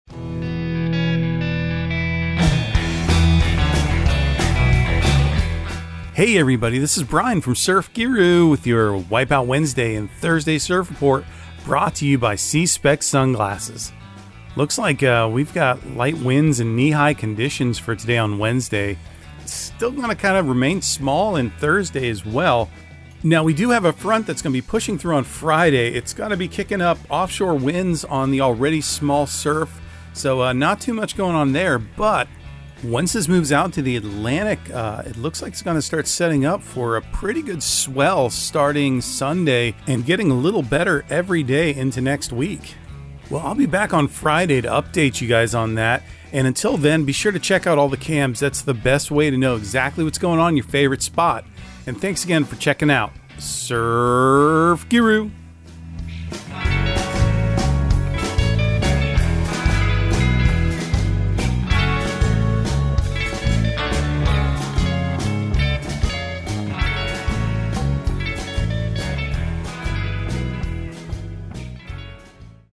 Surf Guru Surf Report and Forecast 01/11/2023 Audio surf report and surf forecast on January 11 for Central Florida and the Southeast.